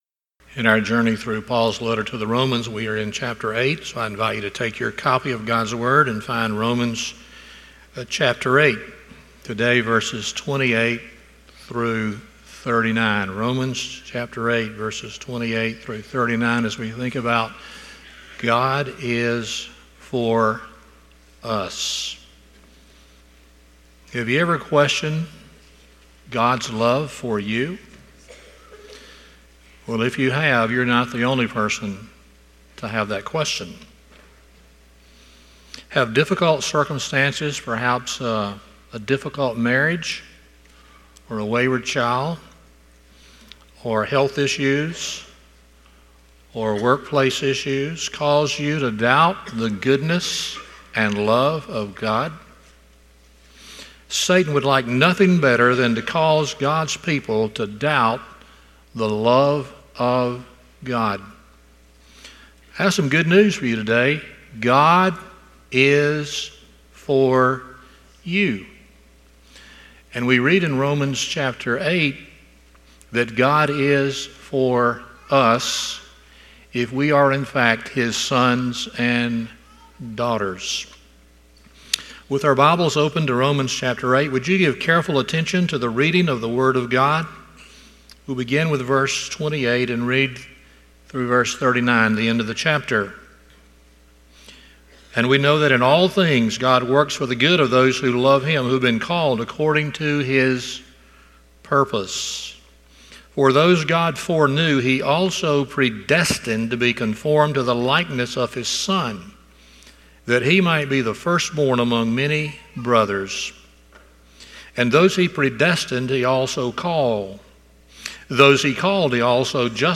Romans 8:28-39 Service Type: Sunday Morning 1.